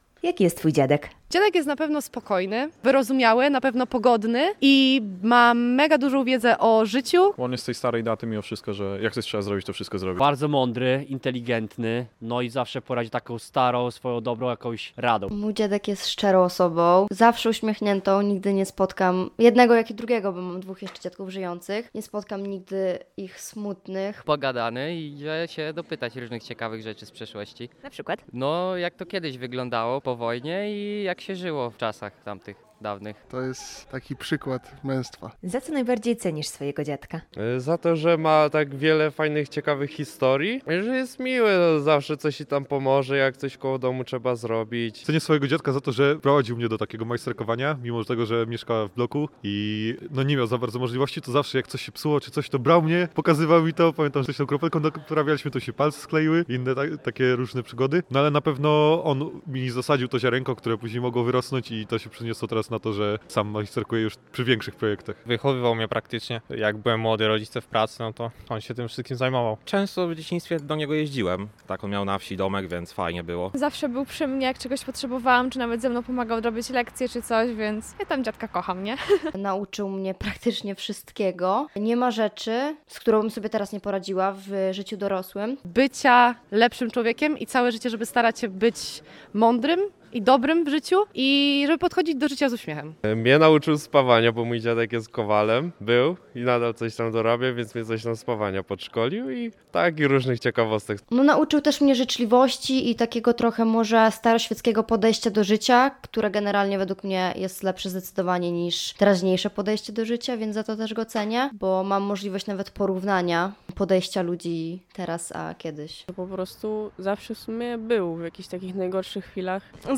SONDA-dzien-dziadka.mp3